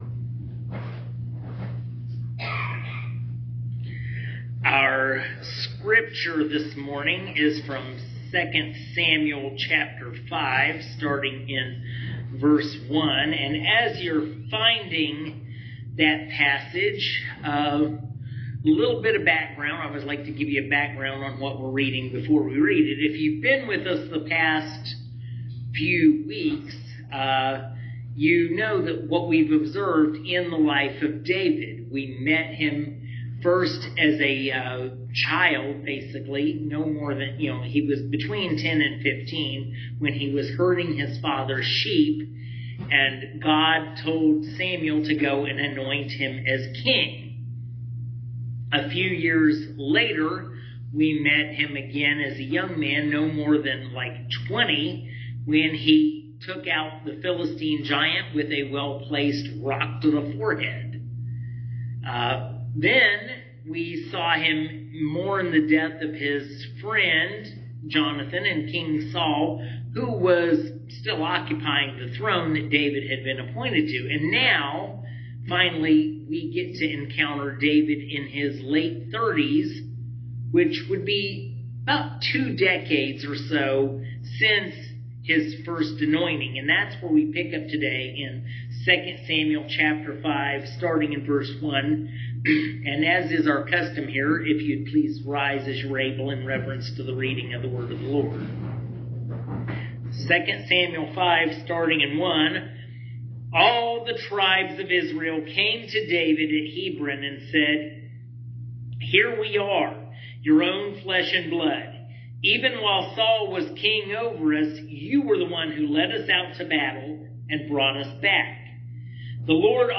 2 Samuel 5:1-10 | The Lord God of Armies – Sappony Baptist Church